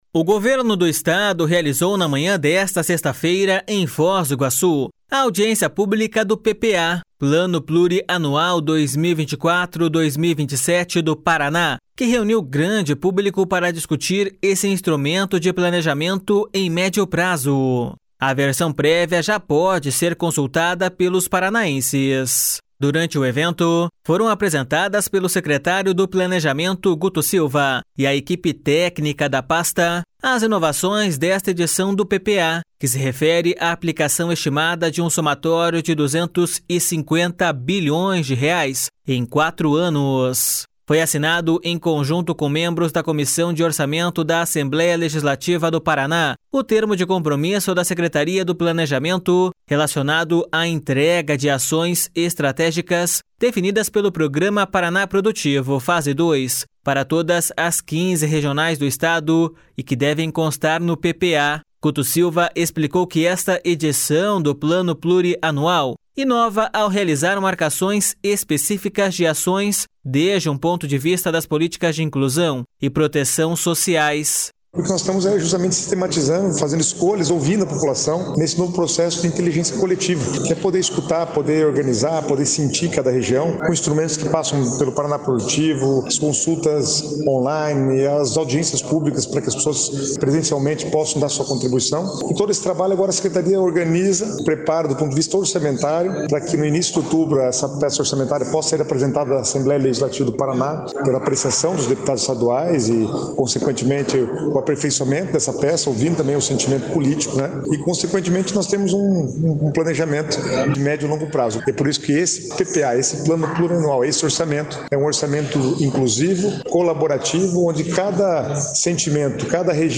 Guto Silva explicou que esta edição do Plano Plurianual inova ao realizar marcações específicas de ações desde um ponto de vista das políticas de inclusão e proteção sociais.// SONORA GUTO SILVA.//